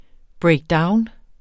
Udtale [ bɹεjgˈdɑwn ]